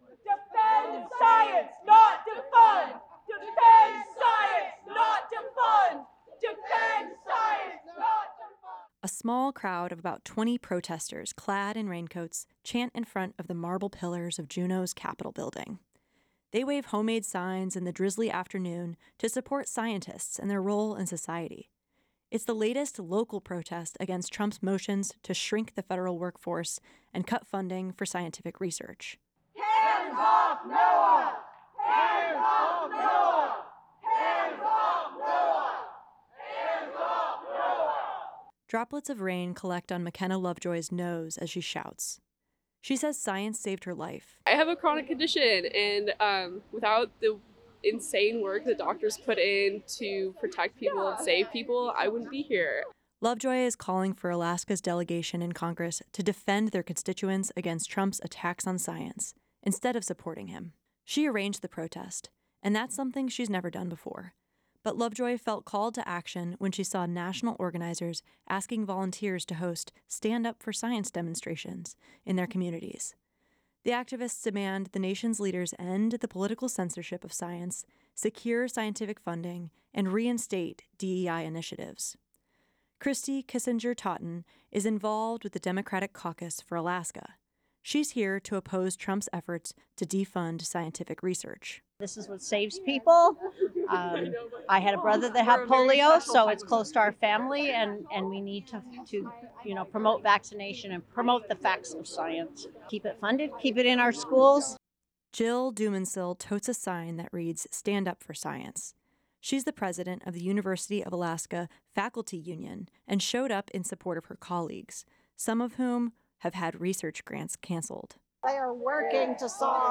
A small crowd of about 20 protesters clad in raincoats raised their voices in front of the marble pillars of Juneau’s Capitol Building on Friday.
Chants included “defend science, not defund,” and “NOAA’s not here to build an ark.”